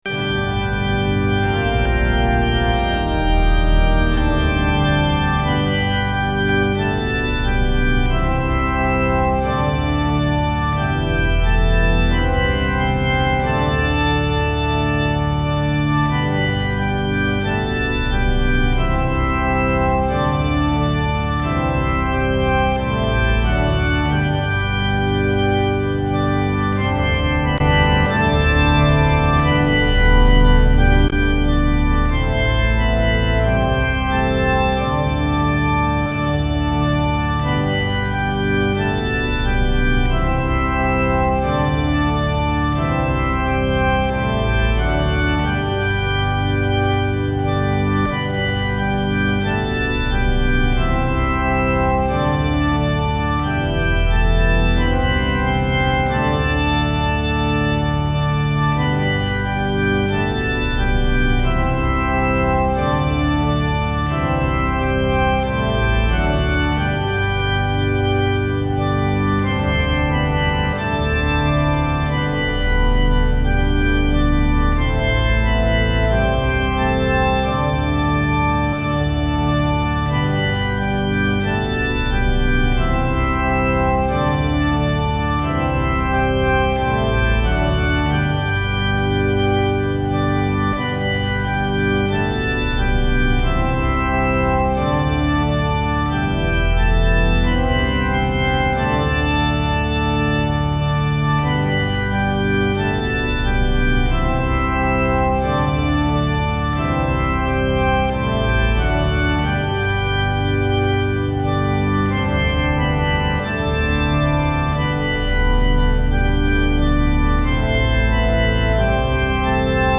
traditional sounding tune
My backing is fake organ at 90 bpm.